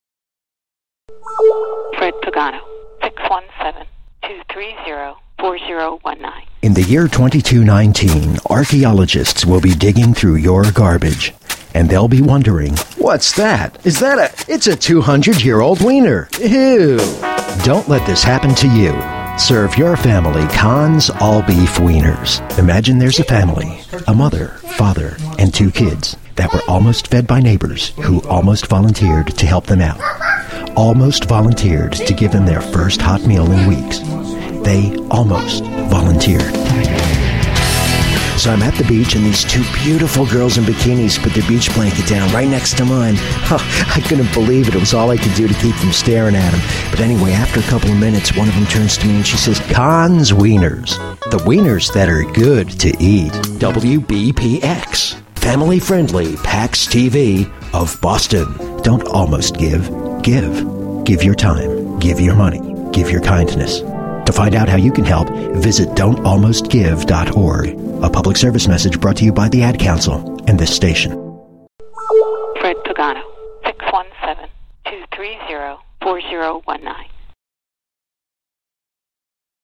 We move from deep to friendly in our first spot for Kahn's weiners, then into ominous voice of god mode for the Don't Almost Give campaign.
commercials.mp3